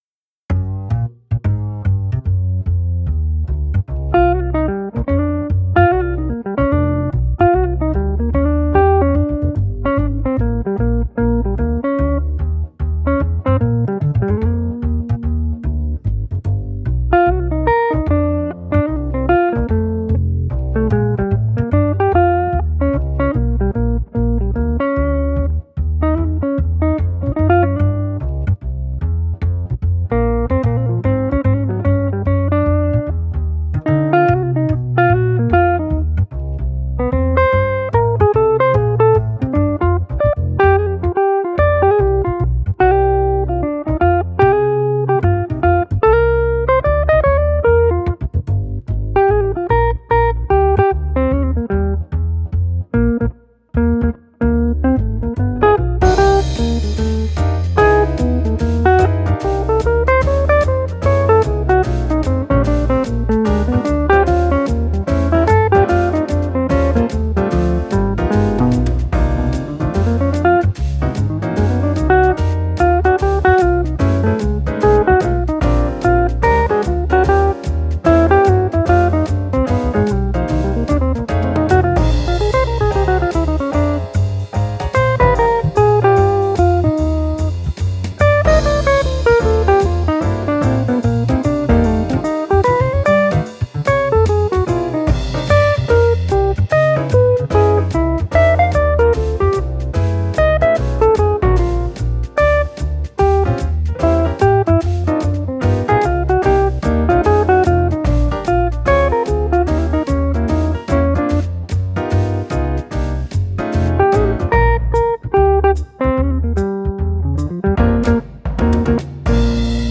There's some lovely runs in there!
Top notch, very nice version!